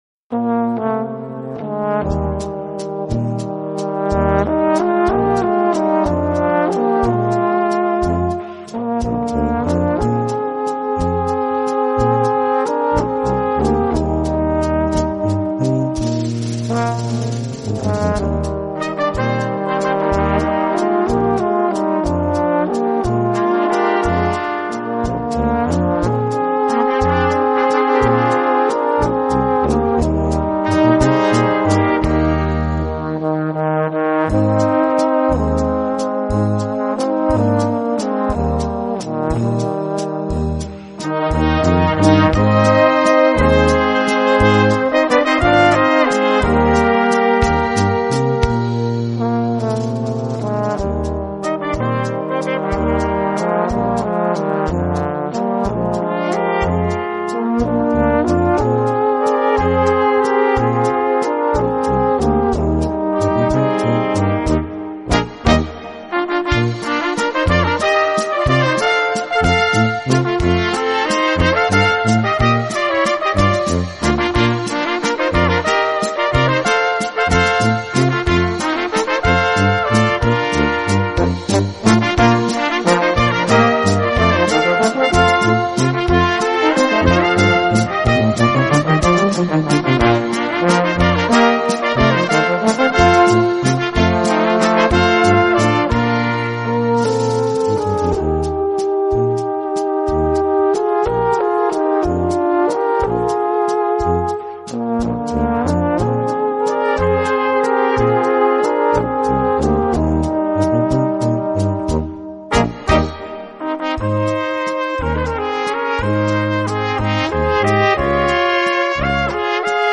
Gattung: Walzer
Besetzung: Kleine Blasmusik-Besetzung
mit virtuosen Solostellen für Trompete und Tenorhorn!